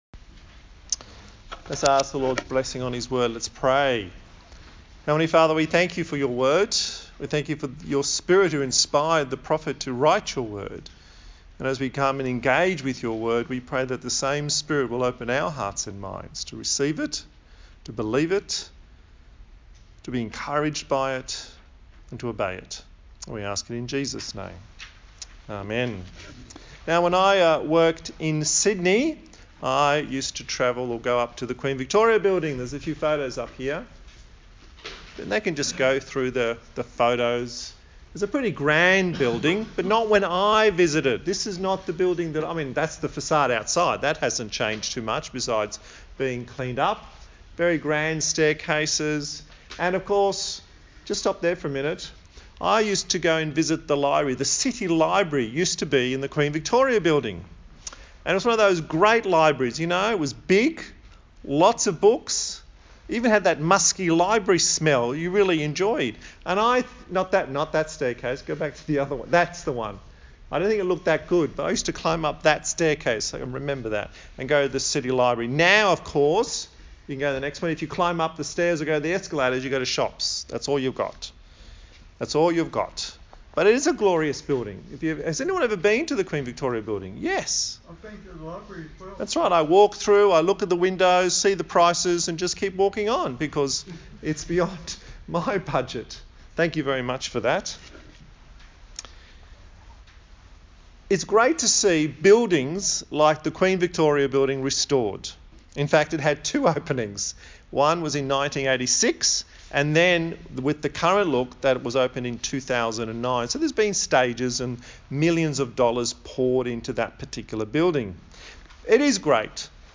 A sermon in the series on the book of Zechariah. God encourages his people with the promise of the Branch.